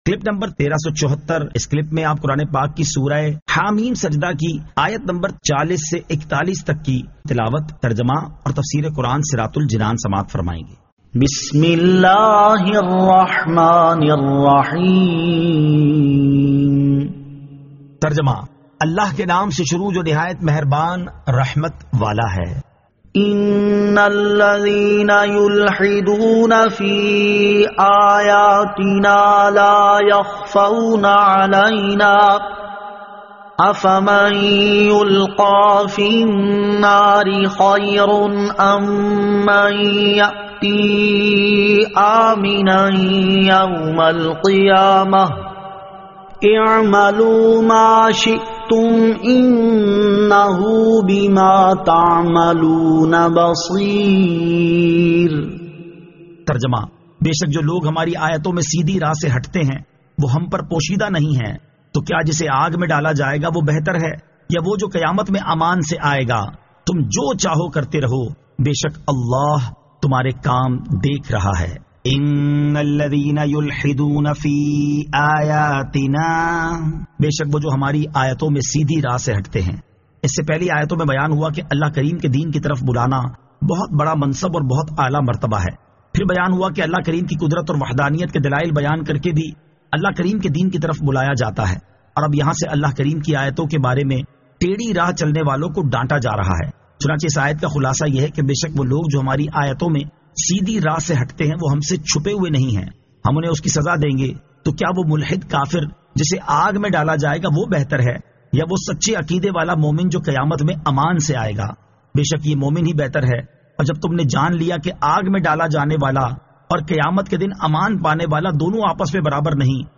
Surah Ha-Meem As-Sajdah 40 To 41 Tilawat , Tarjama , Tafseer